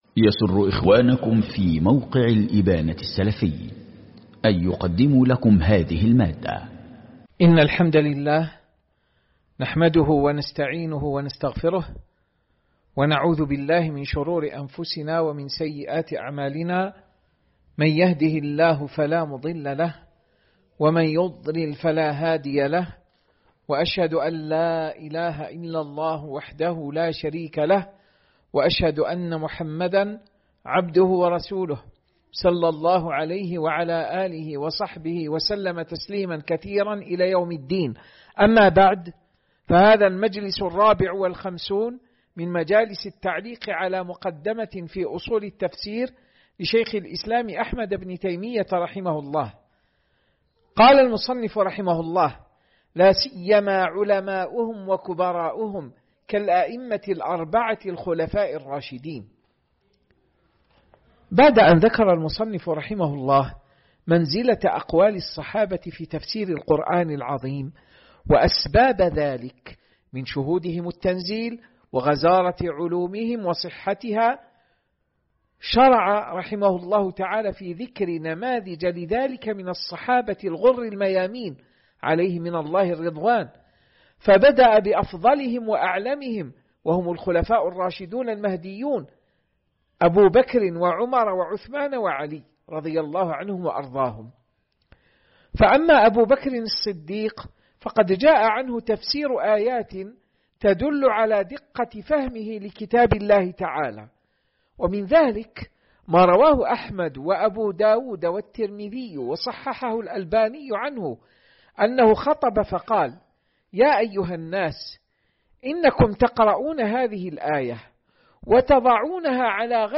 شرح مقدمة في أصول التفسير الدرس 54